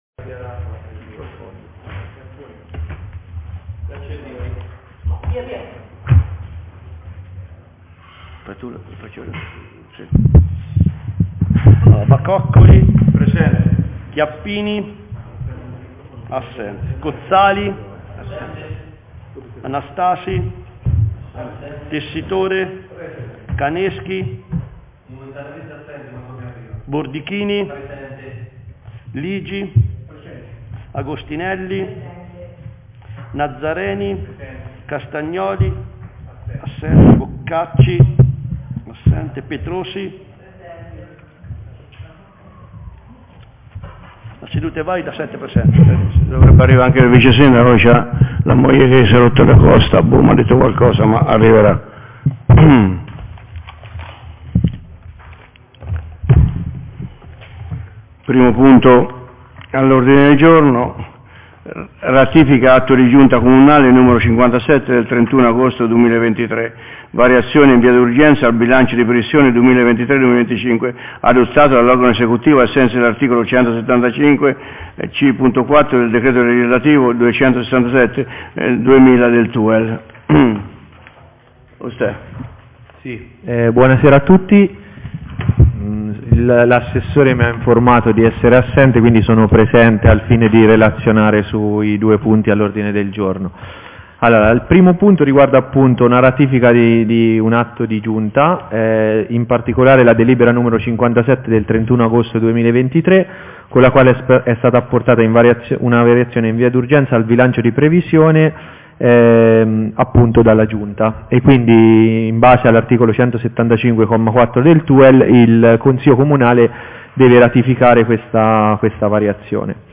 Consiglio Comunale del 5 Ottobre 2023